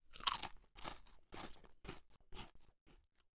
eatingSound.wav